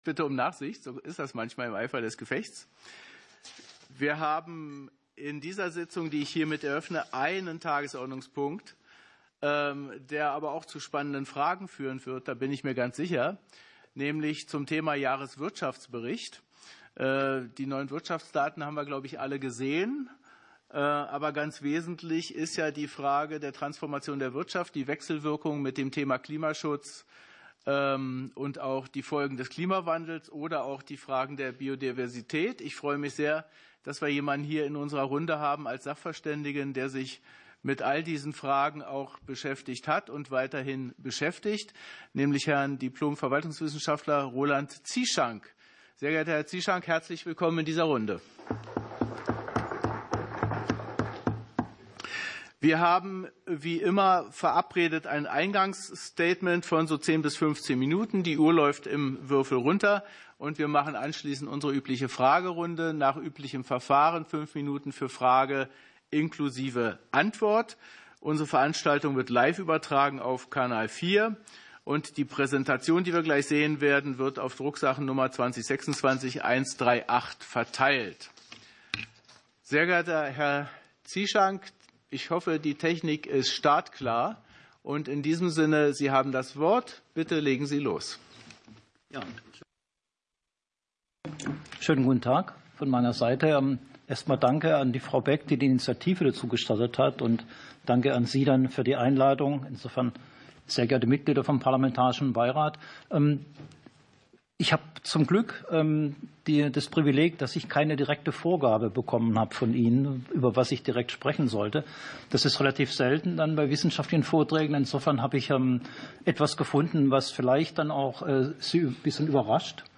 Ausschusssitzungen - Audio Podcasts